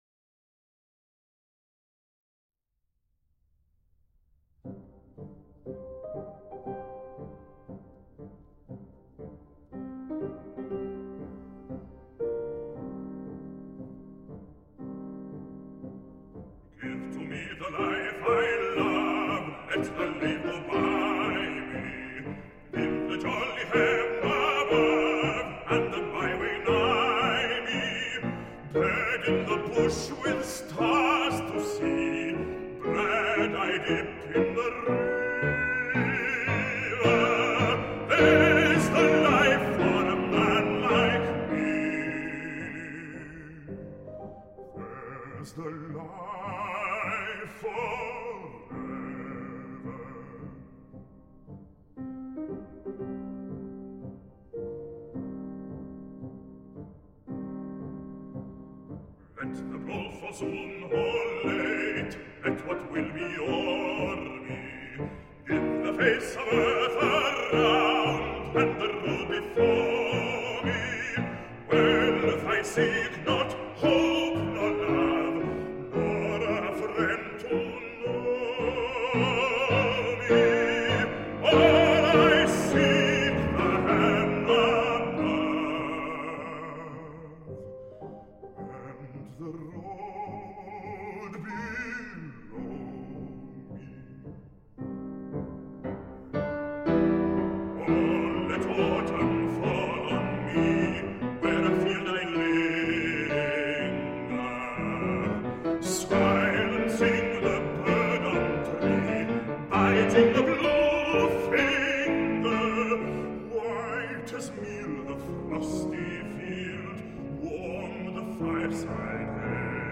男中低音
钢 琴